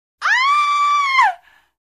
Звуки женского крика